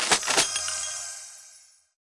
Media:RA_Magical_Shelly_Dep_002.wav 部署音效 dep 局内选择该超级单位的音效